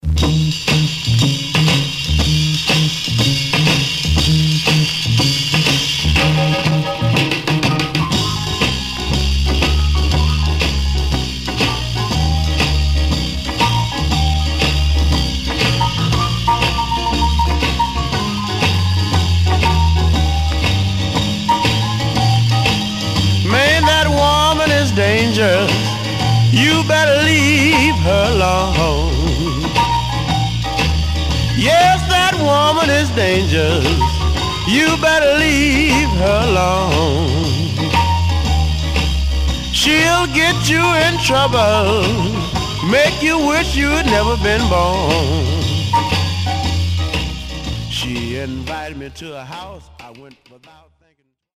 Some surface noise/wear Stereo/mono Mono
Rythm and Blues Condition